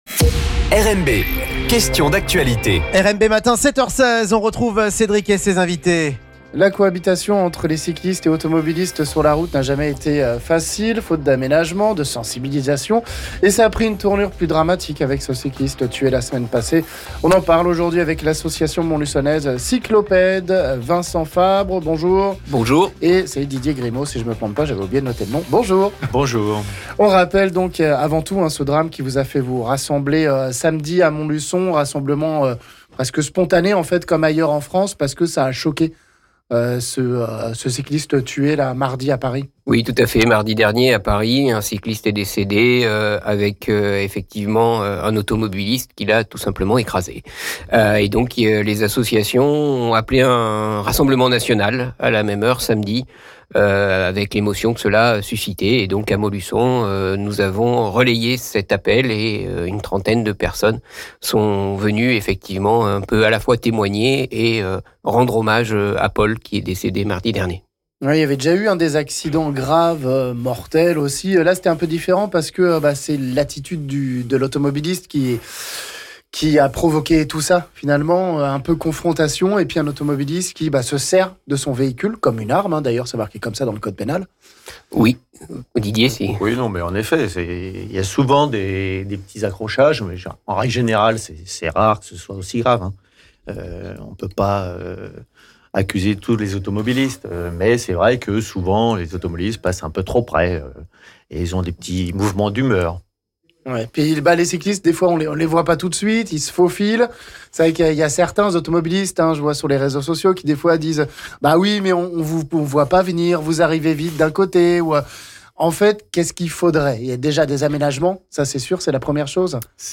On parle de tout ça avec nos invités de Cyclopède